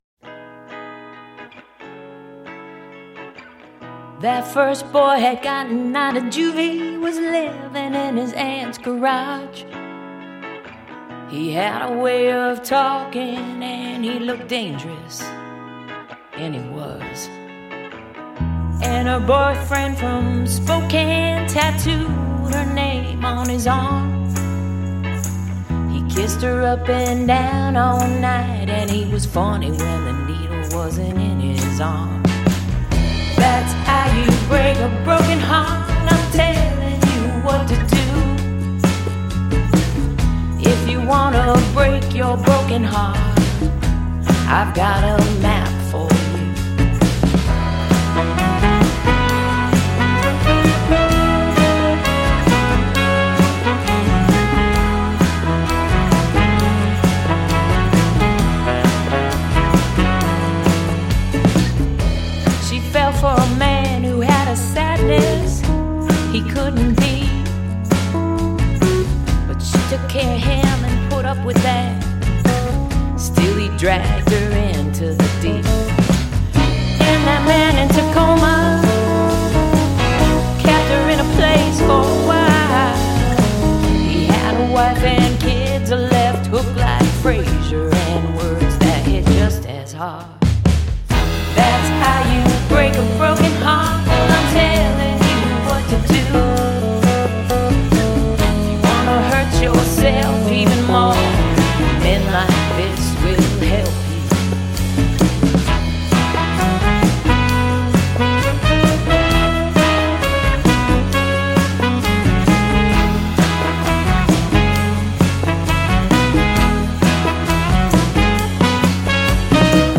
нуарный кантри-соул
трубе